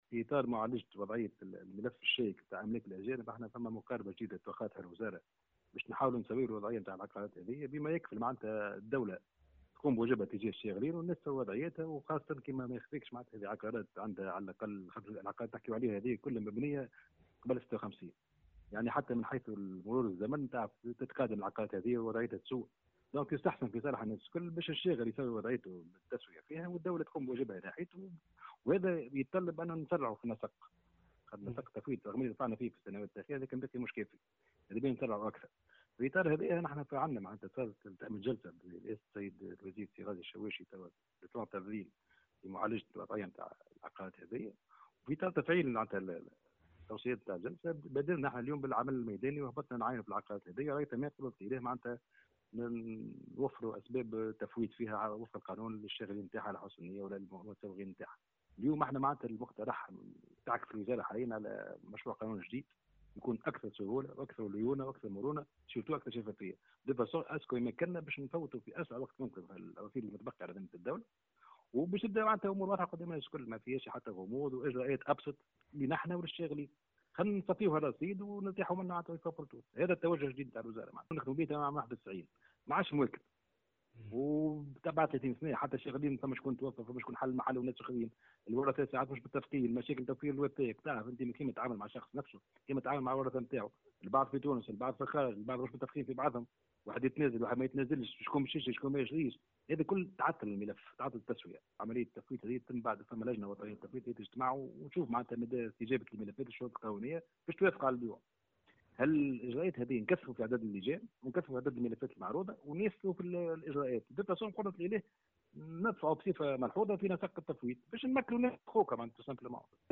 قال مدير عام أملاك الدولة، محمد الصايفي في تصريح اليوم لمراسل "الجوهرة أف أم" إن الوزارة تعكف على إعداد مشروع قانون جديد للتفويت في ما يمكن من العقارات الراجعة للدولة من أملاك الأجانب في أقرب وقت ممكن.